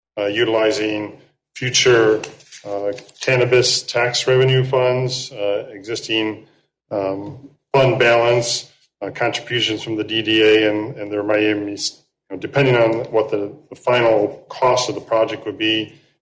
City Manager Keith Baker said they were applying again after being turned down the first time. He ran down the various sources that would fund the local match.